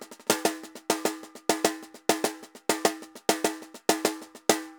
Tambor_Samba 100_2.wav